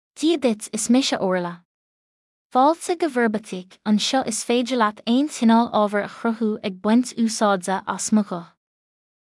Orla — Female Irish AI voice
Orla is a female AI voice for Irish (Ireland).
Voice sample
Listen to Orla's female Irish voice.
Female
Orla delivers clear pronunciation with authentic Ireland Irish intonation, making your content sound professionally produced.